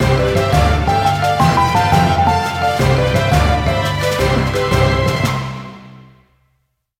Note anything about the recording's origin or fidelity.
Ripped from the game clipped to 30 seconds and applied fade-out